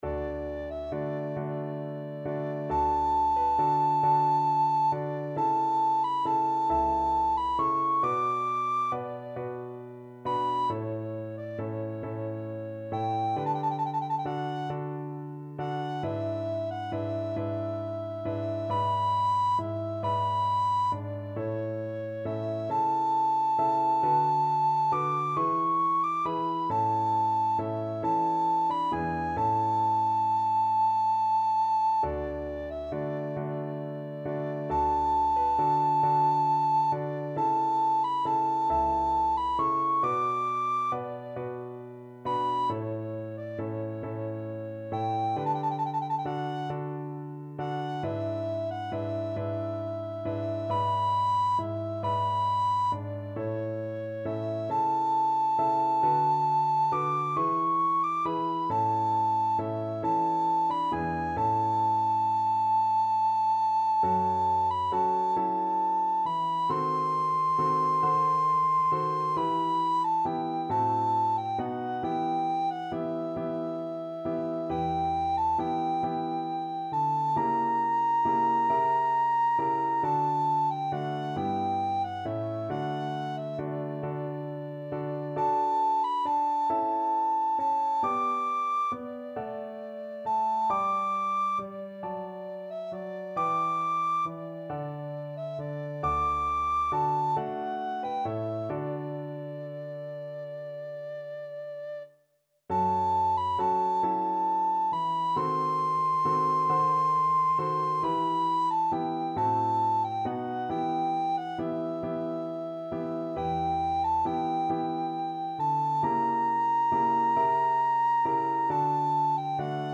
Classical Vivaldi, Antonio Concerto for Flute and String Orchestra 'Il Gardellino', Op.10, No.3, 2nd movement Soprano (Descant) Recorder version
Recorder
D major (Sounding Pitch) (View more D major Music for Recorder )
12/8 (View more 12/8 Music)
II: Larghetto cantabile .=45